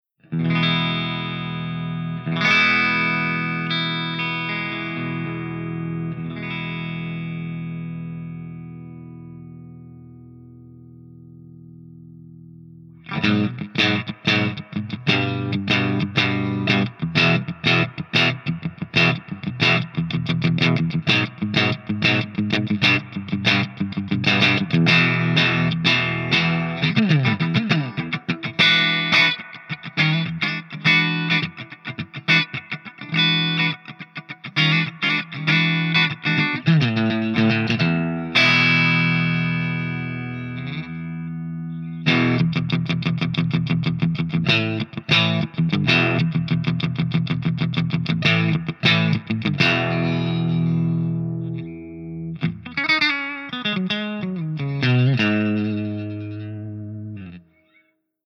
096_HIWATT_STANDARDCRUNCH_GB_SC.mp3